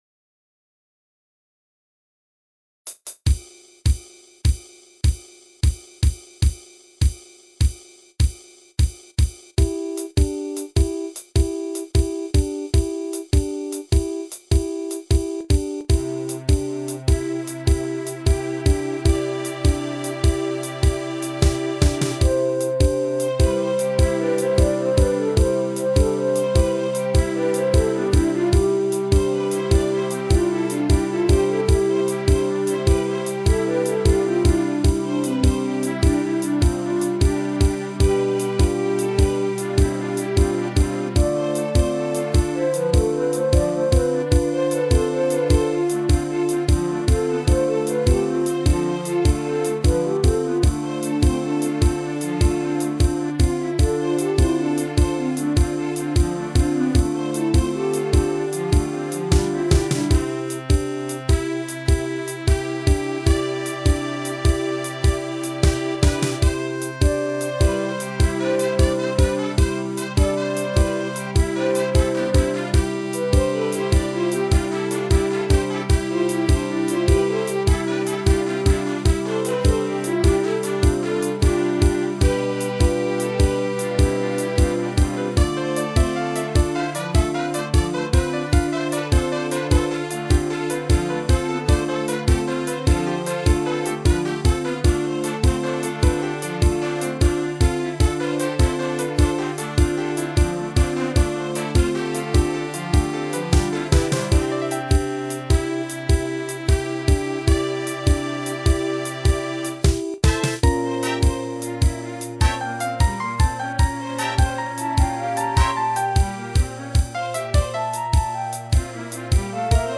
ただしシンバルが登場するエンディングの流れは原曲を尊重。